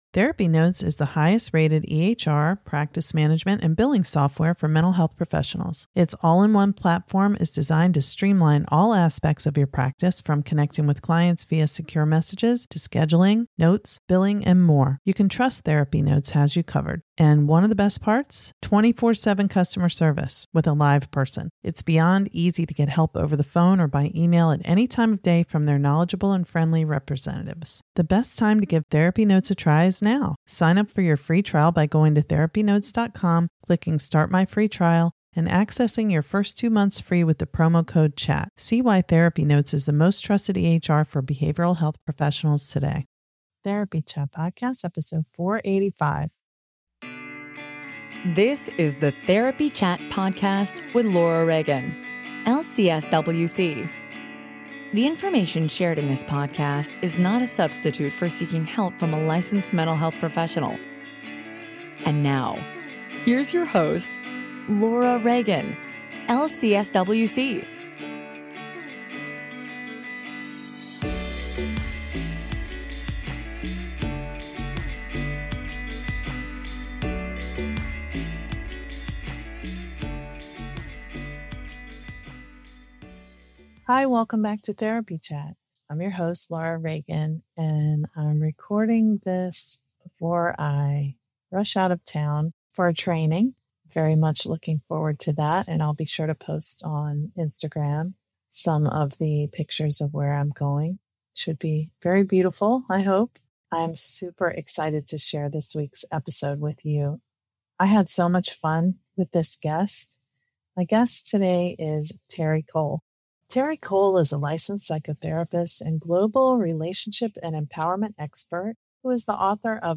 Be a fly on the wall as therapists discuss the practice of psychotherapy and how they implement self care into their own lives to prevent therapist burnout. Conversations about mindfulness, self compassion, The Daring Way™, EMDR, art therapy, Sensorimotor Psychotherapy, other somatic methods, trauma, parenting, attachment will get you thinking deeply about therapy and the universal experience of being human, with all the joy and pain that entails.